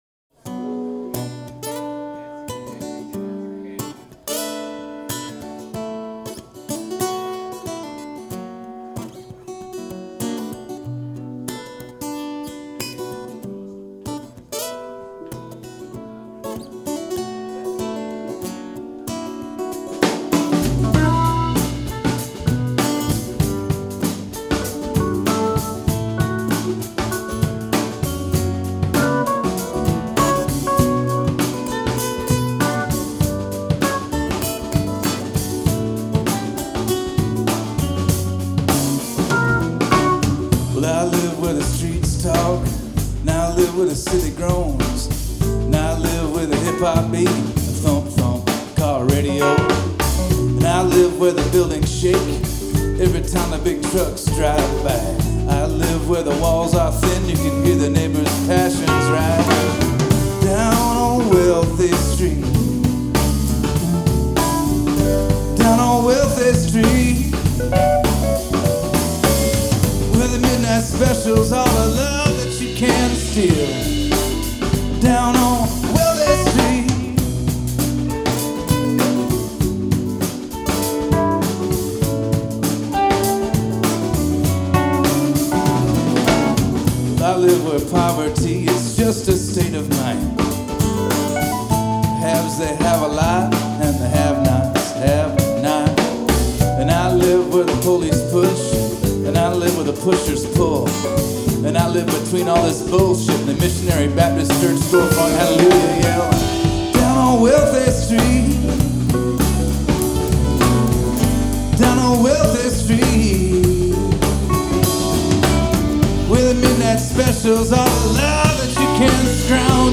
This live recording is from a gig